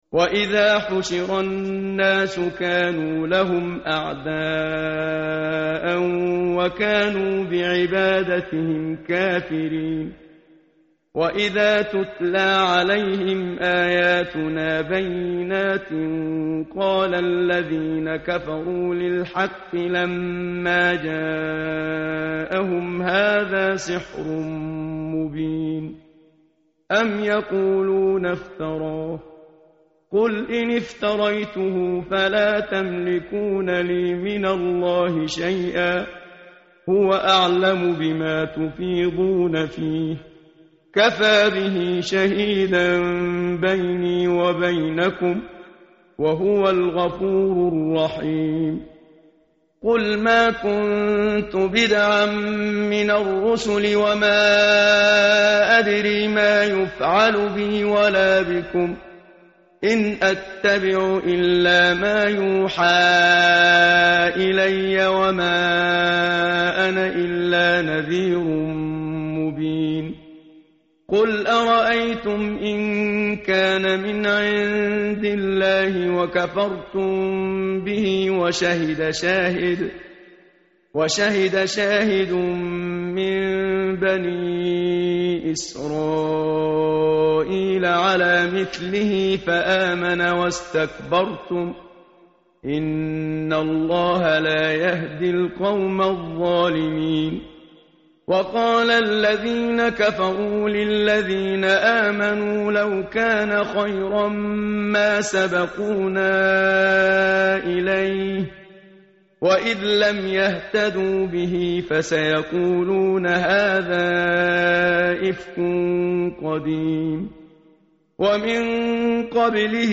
متن قرآن همراه باتلاوت قرآن و ترجمه
tartil_menshavi_page_503.mp3